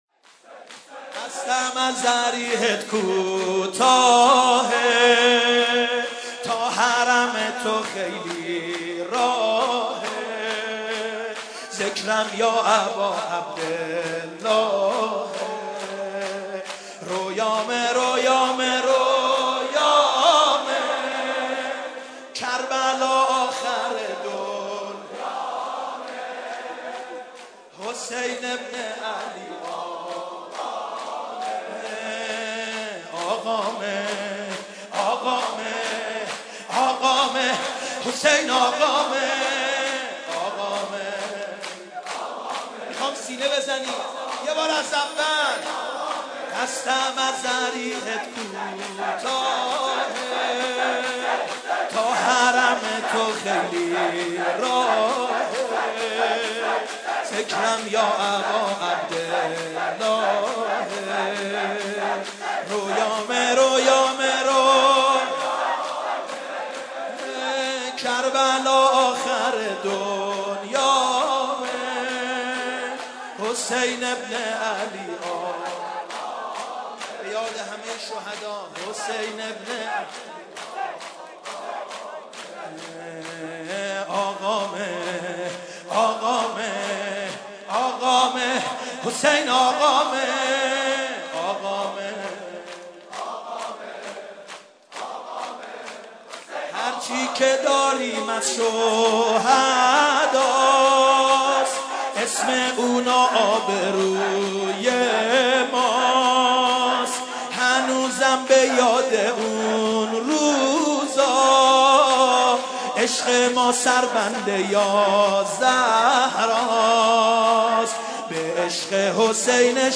maddahi-219.mp3